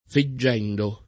fedire v.; fedisco [fed&Sko], ‑sci, o fiedo [